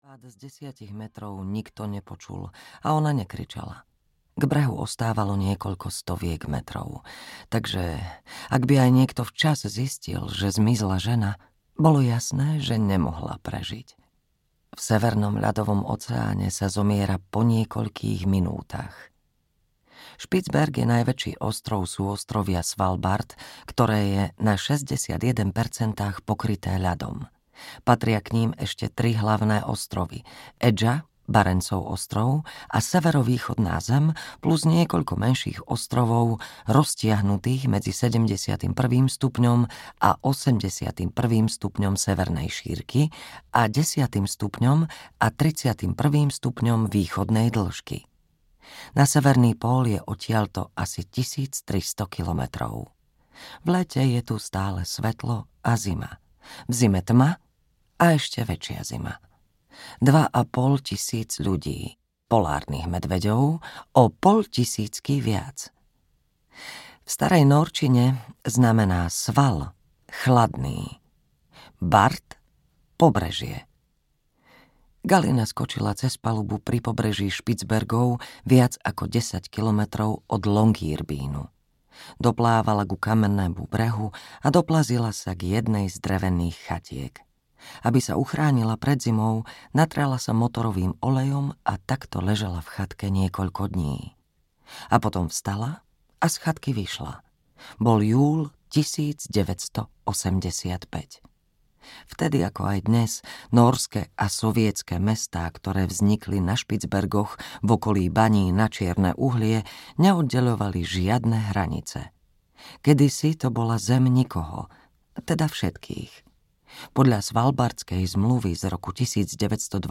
Biele audiokniha
Ukázka z knihy